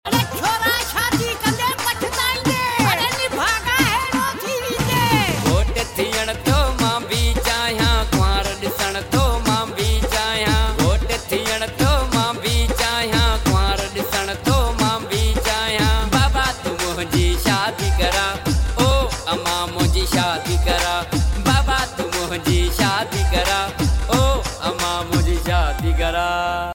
NEW FUNNY SONG